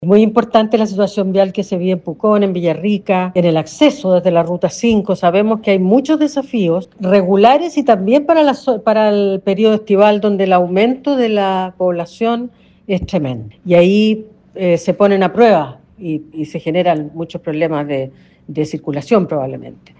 Ministra-MOP-Jessica-Lopez-reconoce-problemas-viales-en-la-zona.mp3